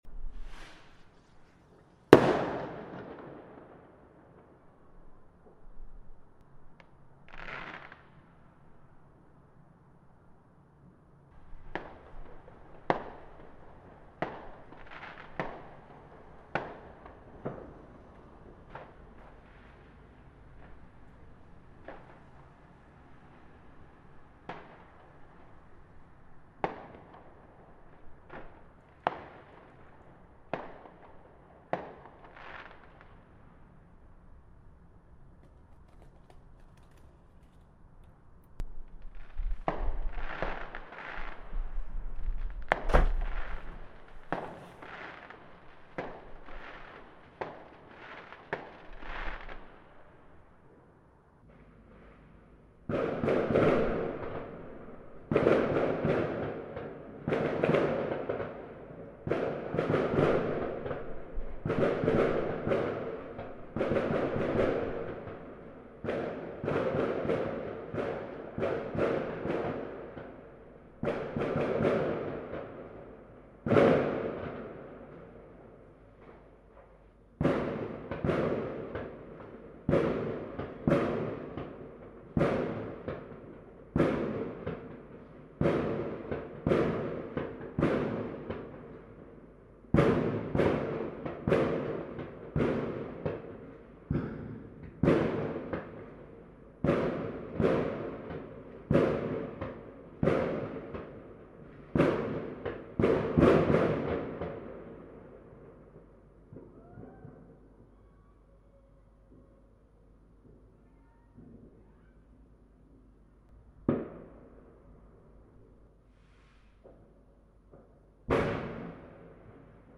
Loud New year fireworks 2023-2024
Edited highlights of the best fireworks recorded in stereo from my window at New Year.